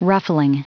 Prononciation du mot ruffling en anglais (fichier audio)
Prononciation du mot : ruffling